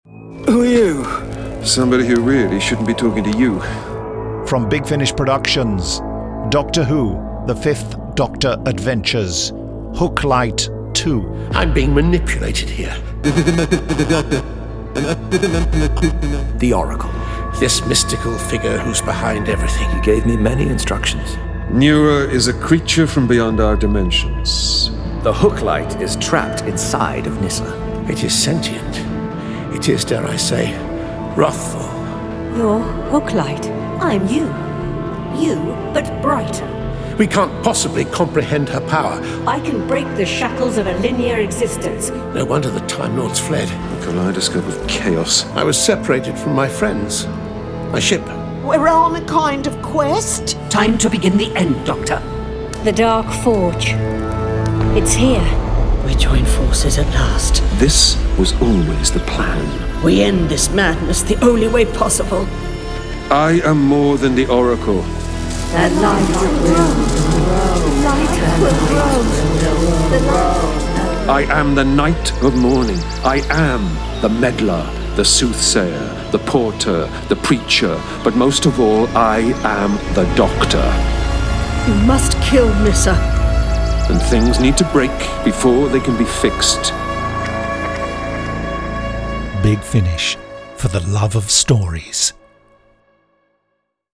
Starring Peter Davison Paul McGann